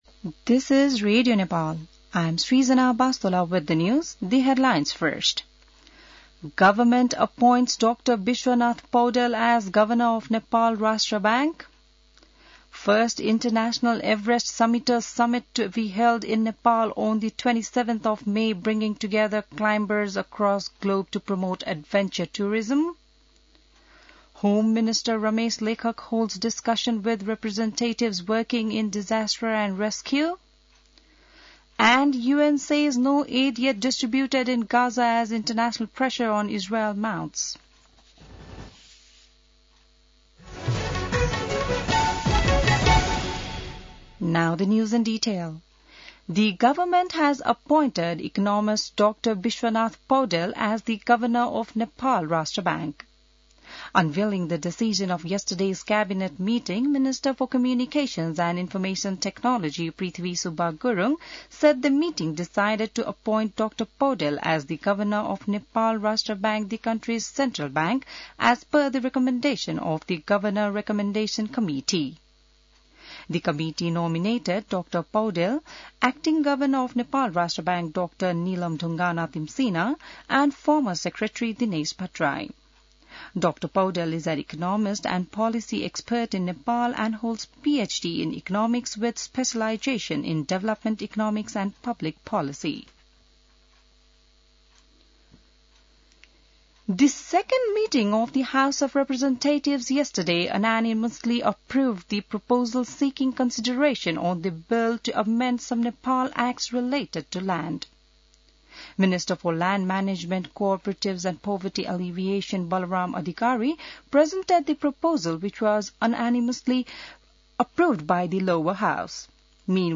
बिहान ८ बजेको अङ्ग्रेजी समाचार : ७ जेठ , २०८२